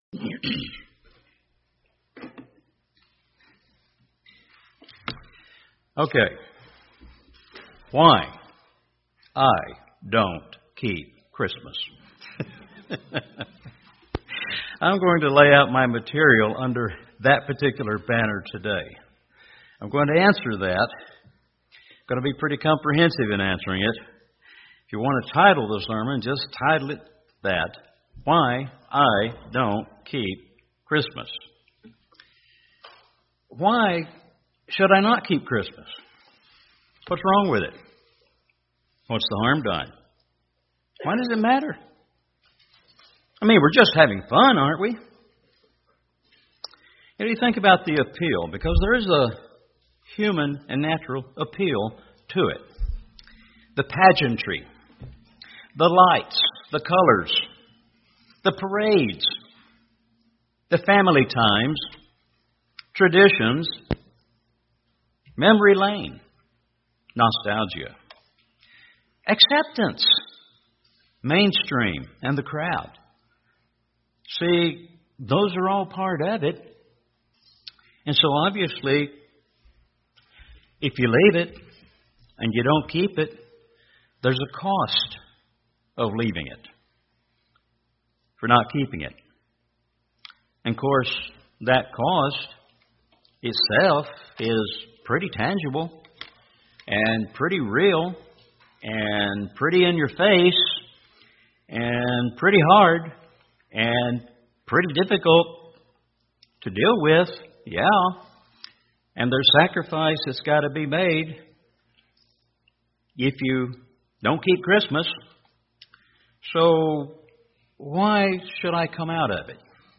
This is a sermon not only relevant at the time of year when this particular holiday is celebrated, but a great beginning of a study of why we honor all of God's Holy Days.